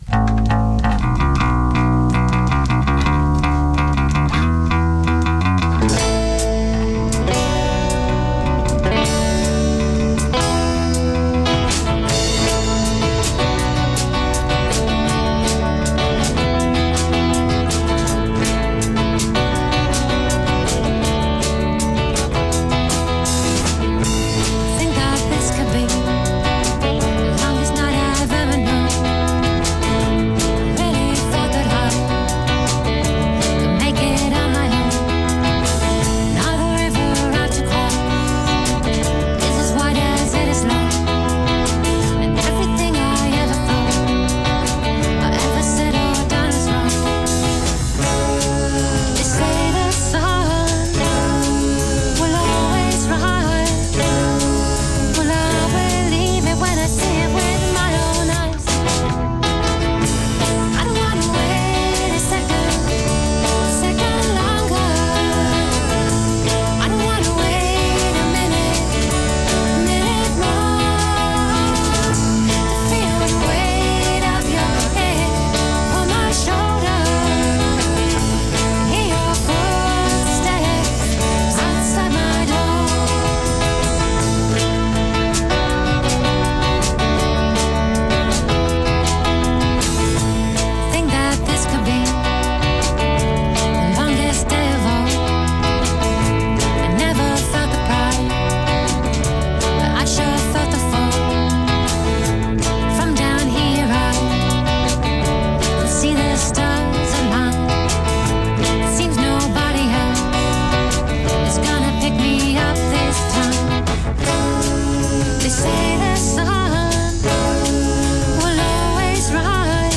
in session for John Kennedy
alternative rock band